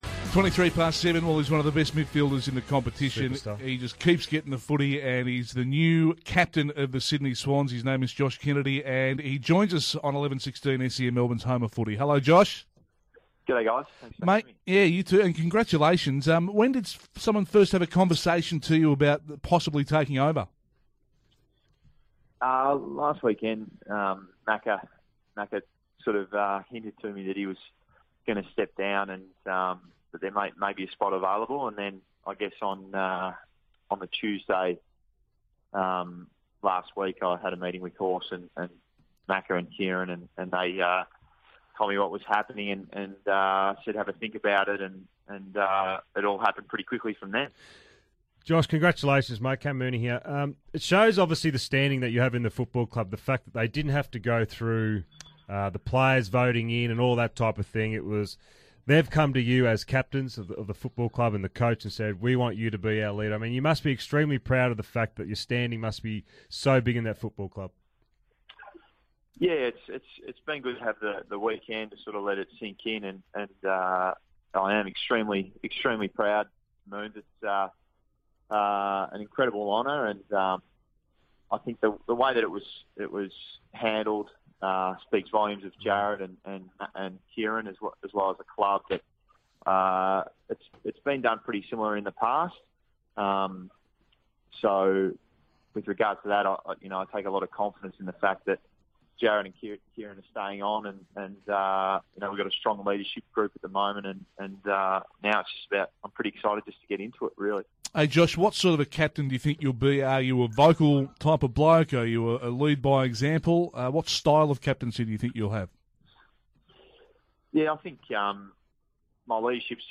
Newly appointed skipper Josh Kennedy speaks to David Schwarz and Francis Leach on SEN Breakfast.